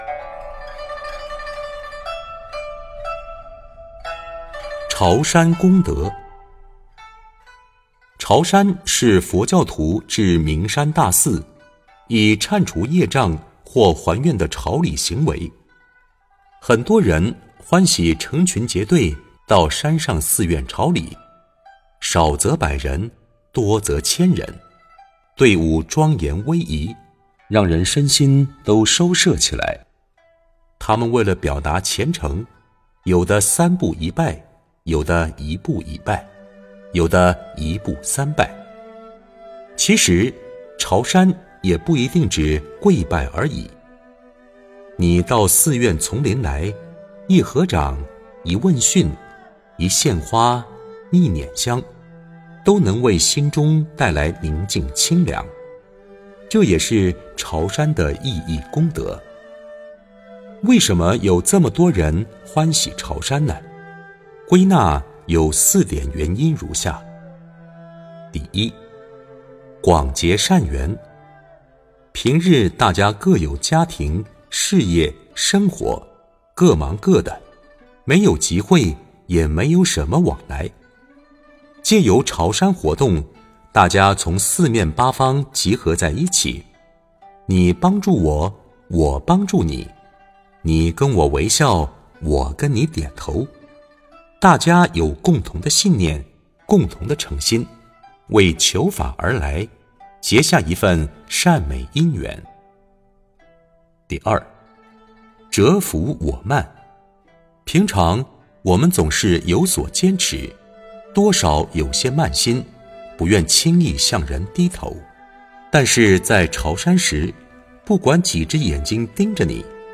佛音 冥想 佛教音乐 返回列表 上一篇： 14.